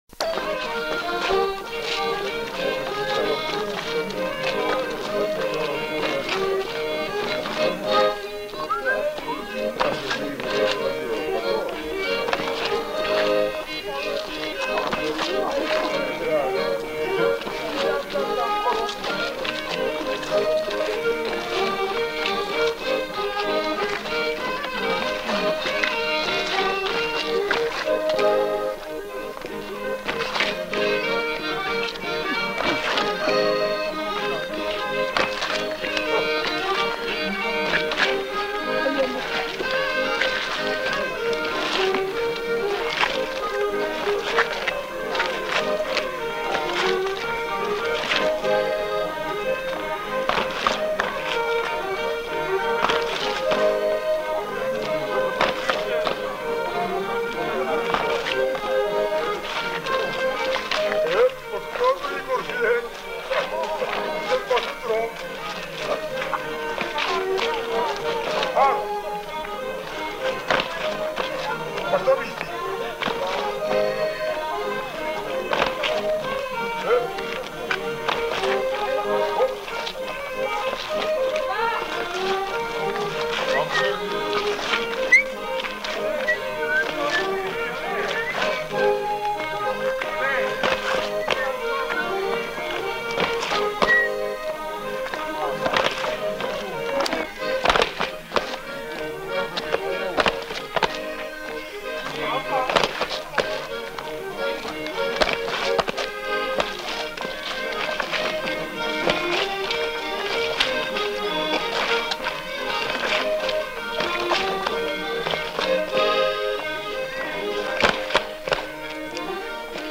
Aire culturelle : Savès
Lieu : Espaon
Genre : morceau instrumental
Instrument de musique : accordéon diatonique
Danse : gigue